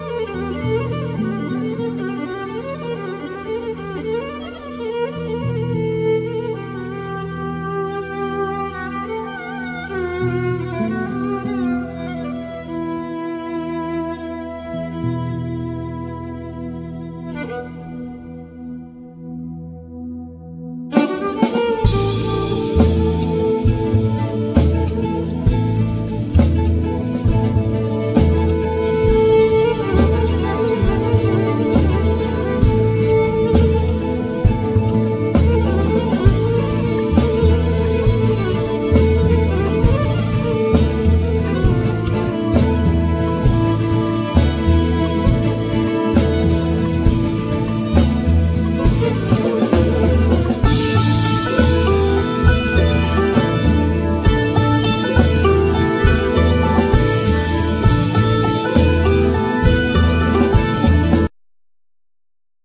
Violin,12 hornes acoustic guitar
Oud
Santouri
Bass
Keyboards
Drums
Percussions
Lyra